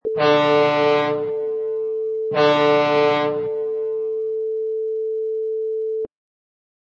Sound: Fog Horn
A ship's fog horn blasting off
Category: Vehicles / Watercraft - Ships
Try preview above (pink tone added for copyright).
Fog_Horn.mp3